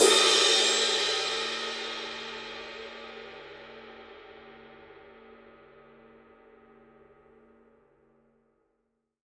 Ride, Signature Dark Energy
Volume: faible à puissant
Son avec la baguette: prononcé, dynamique
Intensité: vive et extrêmement contrôlable
Sustain: moyen
Caractère de la cloche: sombre, pleine, intégré au halo
Caractère du son: Un concentré de fréquences sombres, de projection et de brillance. Spectre très large, mix complexe et musical. Réponse rapide, extrêmement contrôlable. Ping chaleureux, généreux et claquant sur un halo riche. Ride très polyvalente, s’adaptant à de nombreux styles de musique moderne.
Mark II : Tend à être plus expressive et claire. Cela se manifeste dans le son global et en particulier dans le son de la cloche et du ping. Mark II a aussi plus de volume potentiel dû à son caractère plus ouvert.
signature_dark_energy_20_dark_energy_ride_mark_ii_edge.mp3